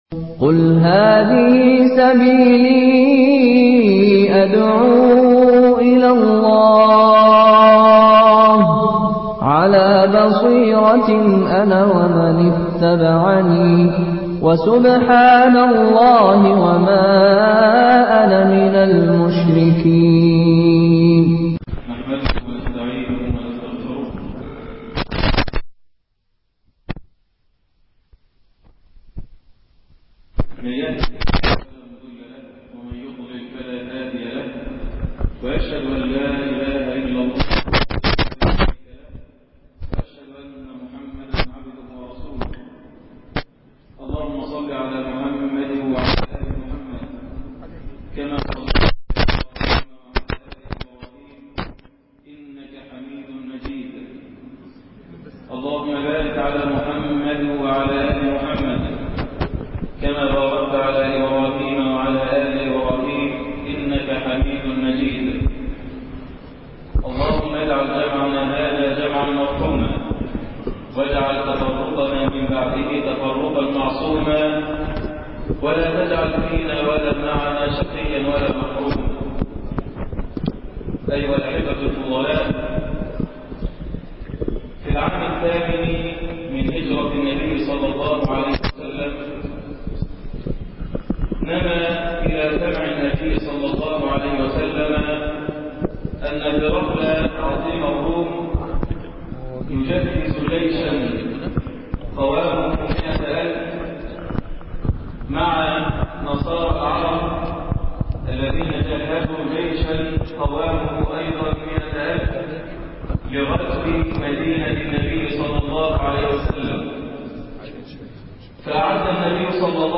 مؤتمر بالمجمع الاسلامى بورسعيد